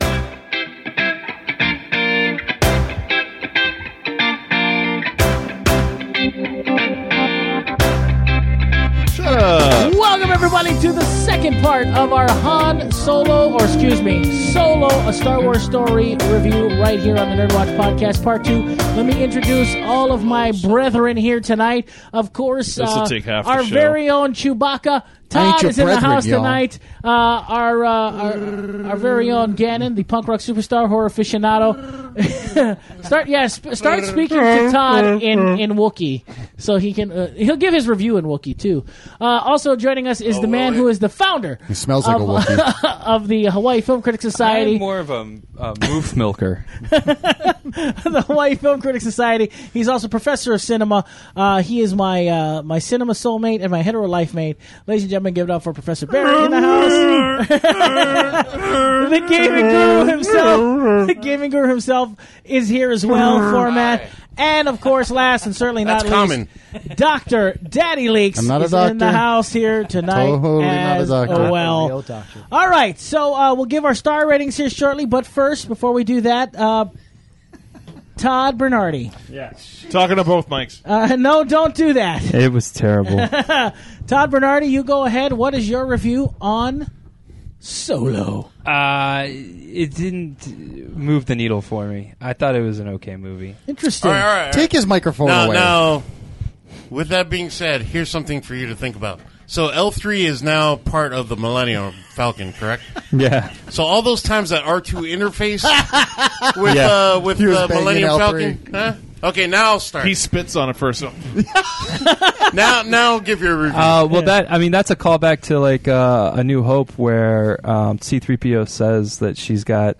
All this recorded live at Maui Comics & Collectibles.